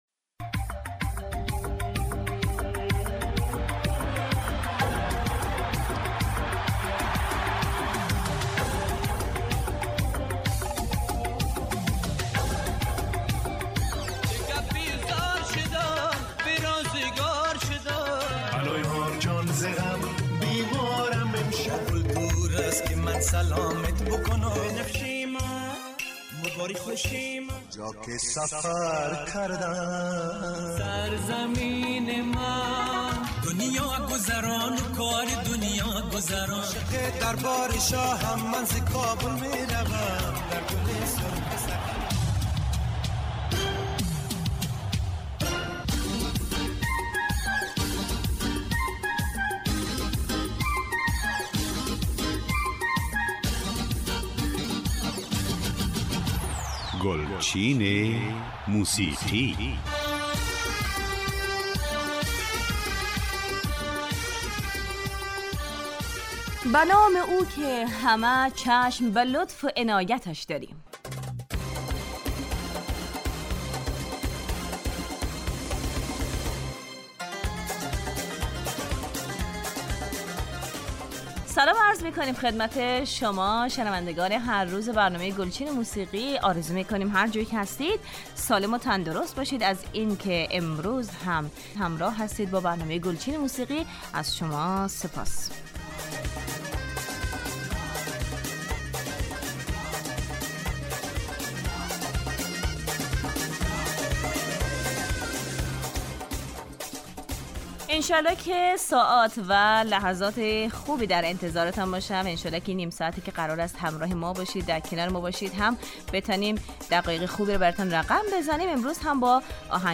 برنامه ای برای تمام سنین که ترانه های درخواستی شما عزیزان را پخش می کند